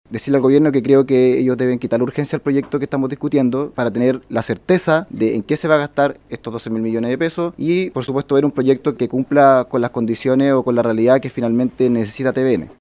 En este contexto, el diputado independiente, Sebastián Videla, pidió al Ejecutivo quitar la urgencia a la iniciativa “para tener la certeza de en qué se van a gastar estos $12 mil millones y, por supuesto, ver un proyecto que cumpla con las condiciones o realidad que necesita TVN”.